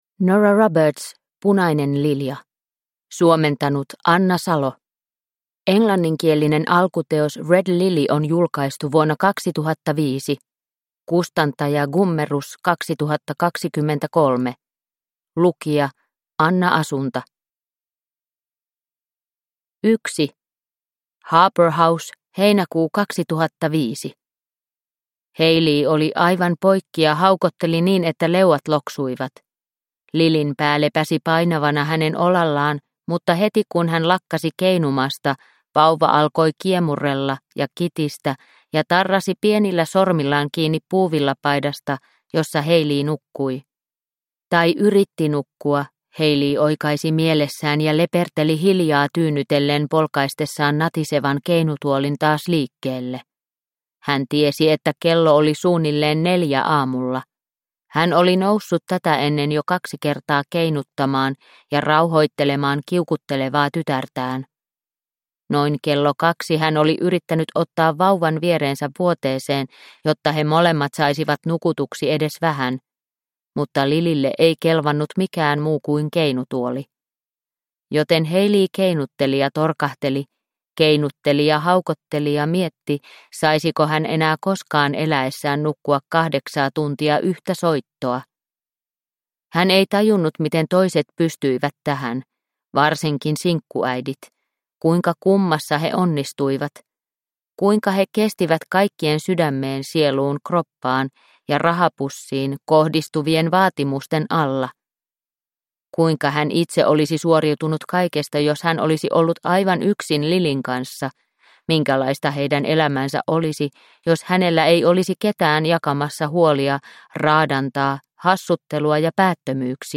Punainen lilja – Ljudbok – Laddas ner